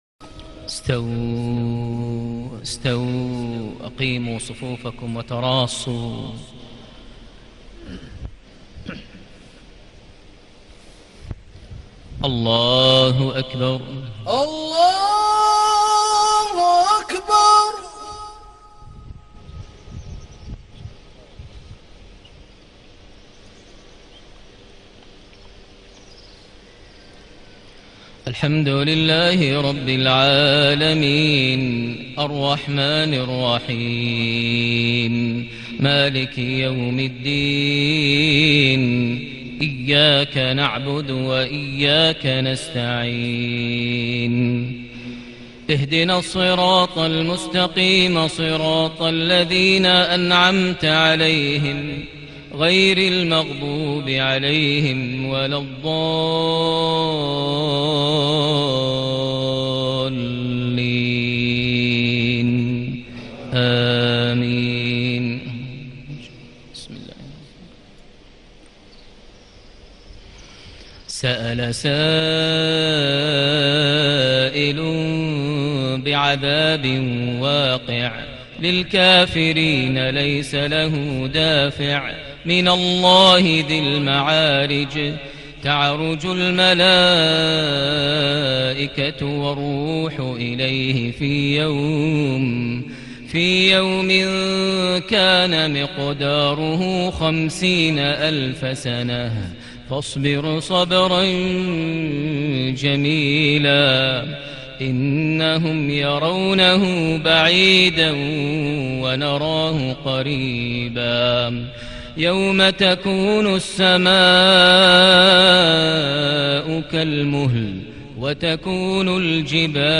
صلاة المغرب ٢ صفر ١٤٣٨هـ سورة المعارج ١-٣٥ > 1438 هـ > الفروض - تلاوات ماهر المعيقلي